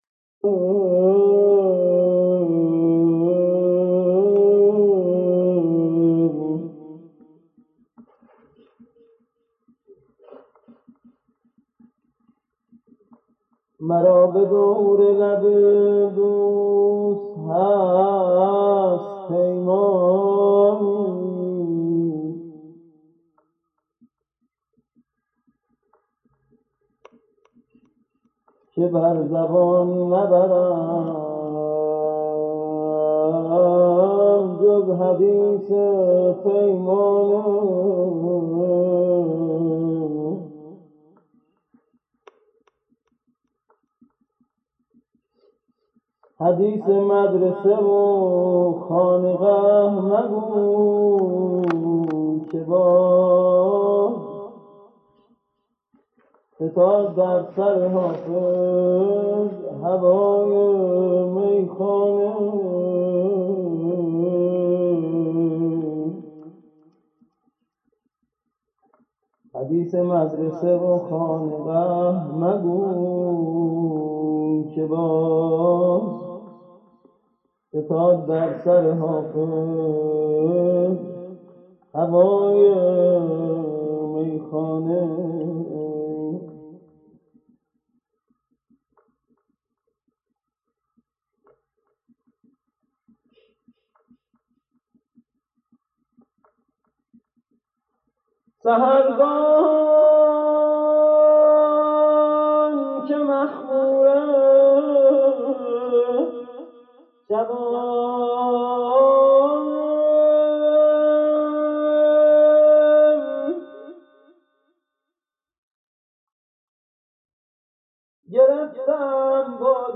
مدائح و مراثی
قرائت اشعار